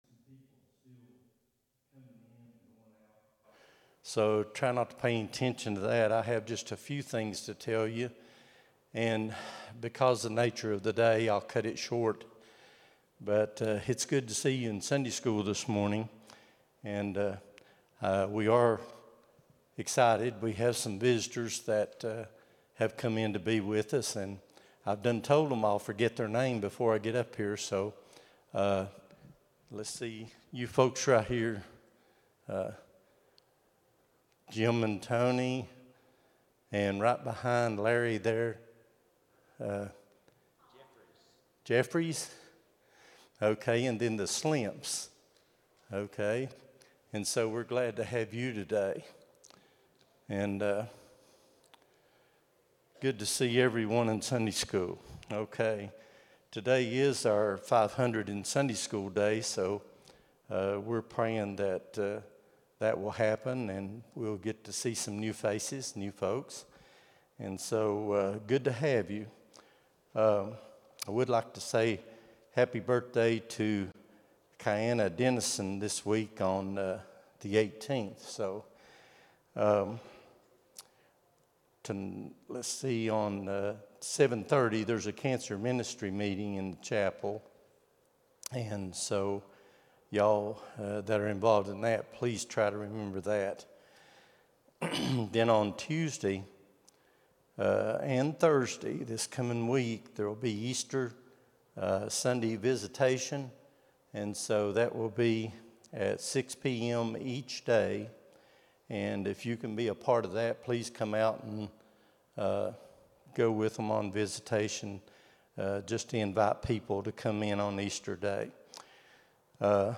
04-13-25 Sunday School | Buffalo Ridge Baptist Church